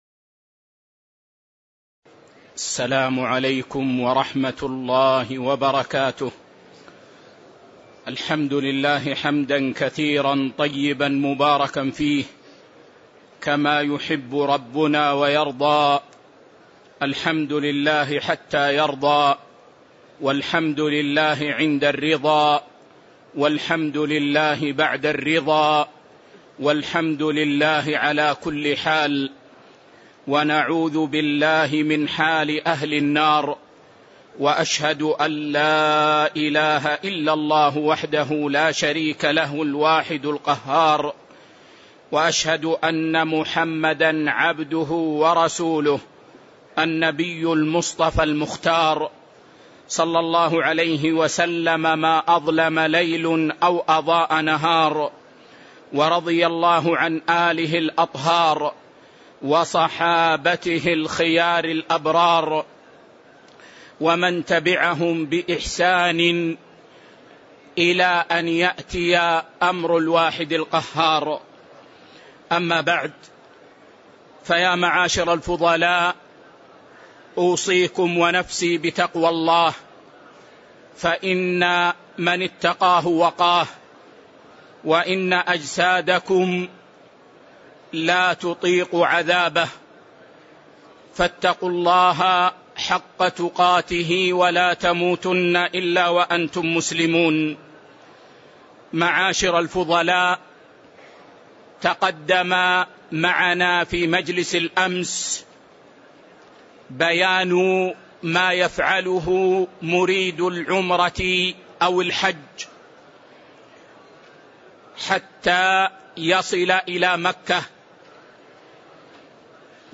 تاريخ النشر ٢٧ ذو القعدة ١٤٣٩ هـ المكان: المسجد النبوي الشيخ